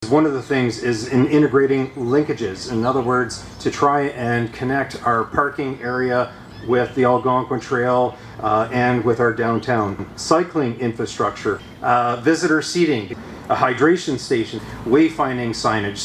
Pembroke Mayor Ron Gervais was on hand to welcome the news and he commented that part of the importance of the new funding is that it represents a way of bringing together the already existing aspects of the waterfront and downtown core into once cohesive wonder right here in Pembroke: